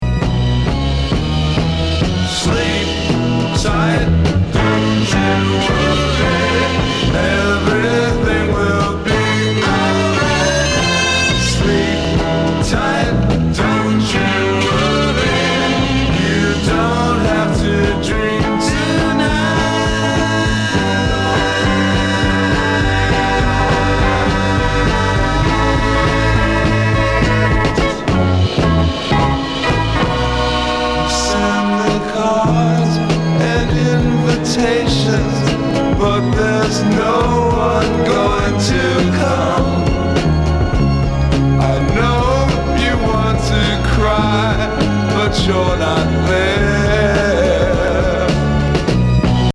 心臓音から始まる、69年USサイケの名盤!強烈なコズミックぶりで炸裂するファズ
＋オルガンを導入した、メロウ～ヘヴィ～エクスペリメンタル作!